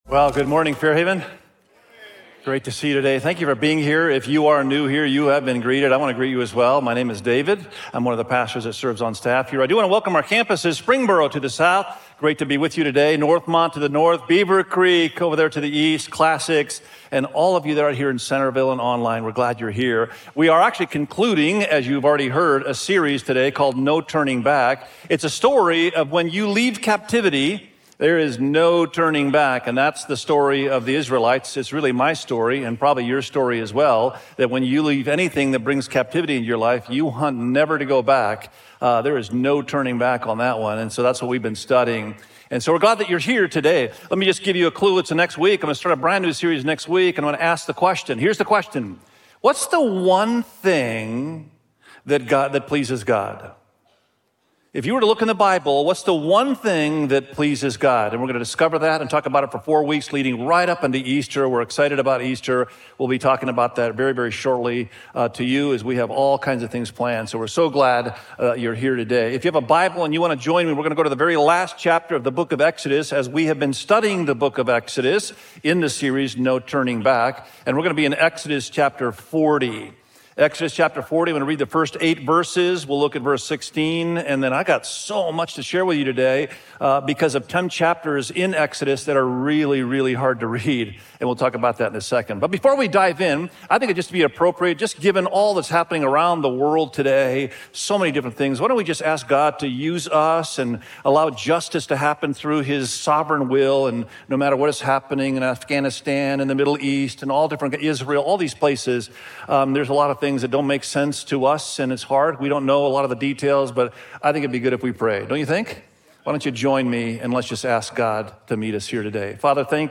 Gods-Presence-Among-His-People_SERMON.mp3